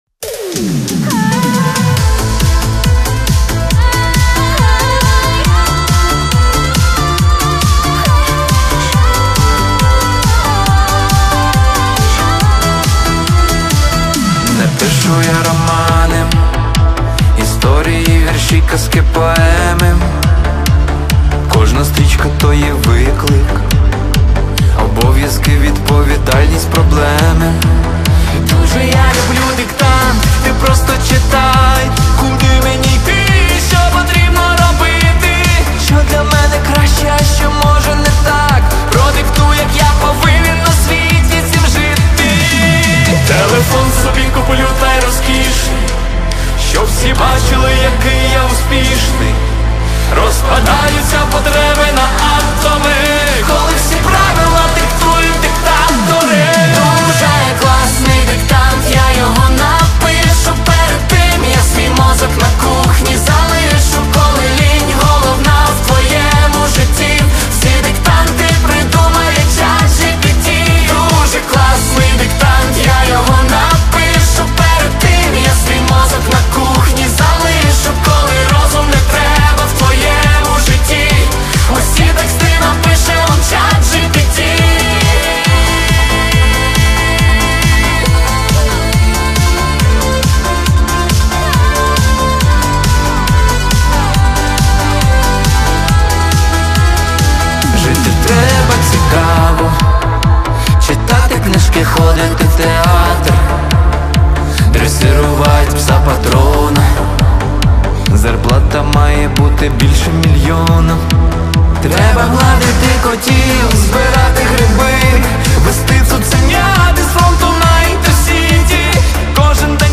• Жанр: Електронна